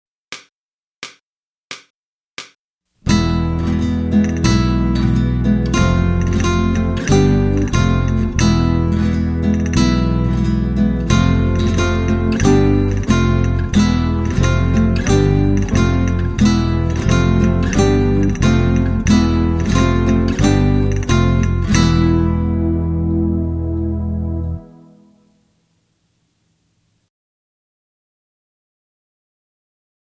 • Instrumentation: Guitar